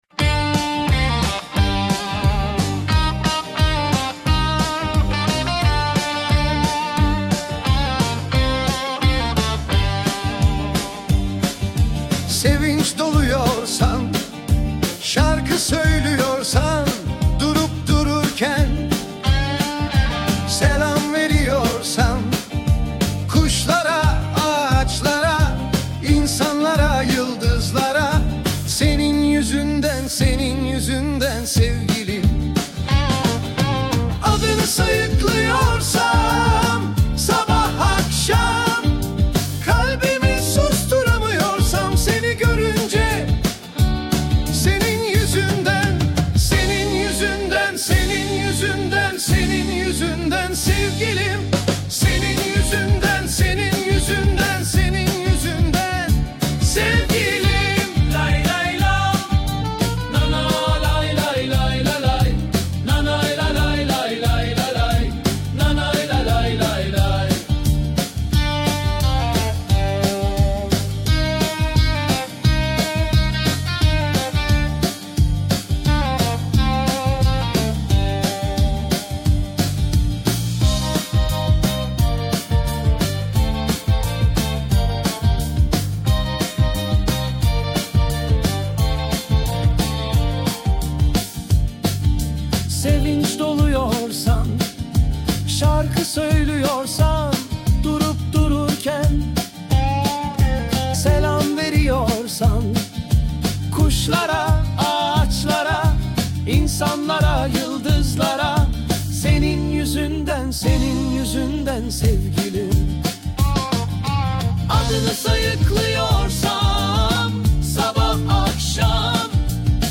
Tür : Pop, Rock